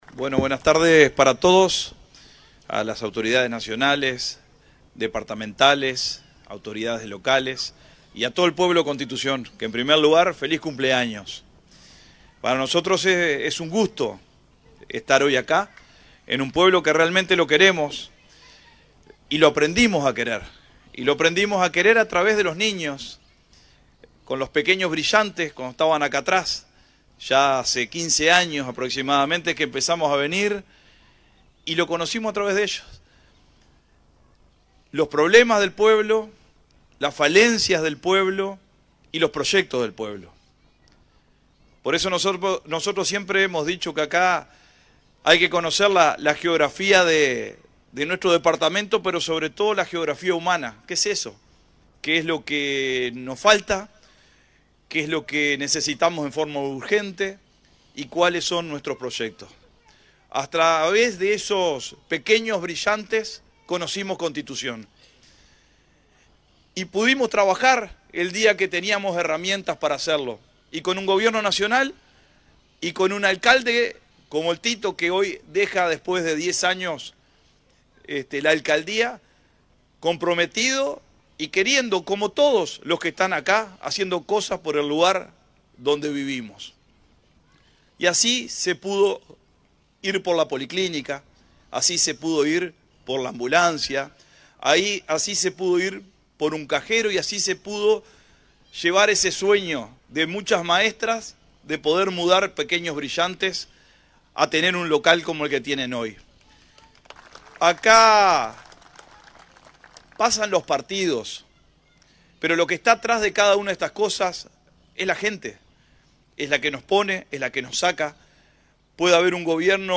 Finalmente, el intendente de Salto, Dr. Carlos Albisu, cerró el acto con un mensaje de respaldo institucional al nuevo alcalde y a la comunidad de Villa Constitución.